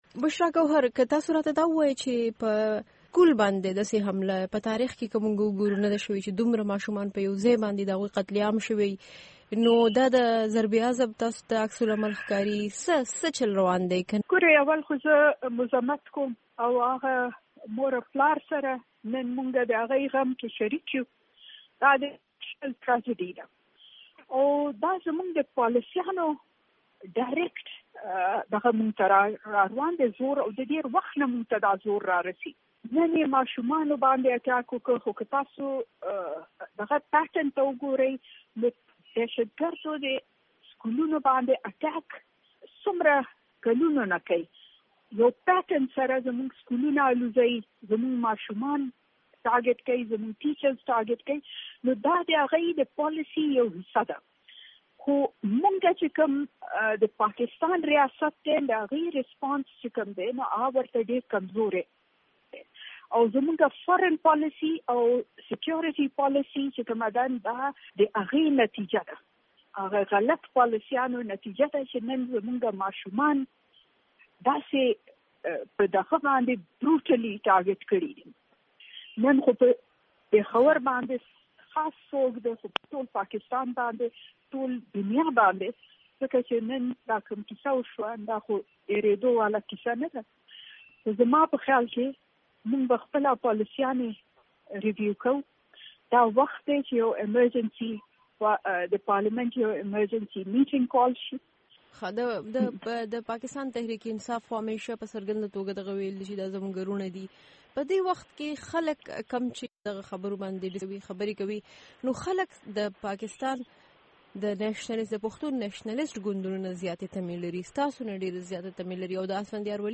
په مرکه کې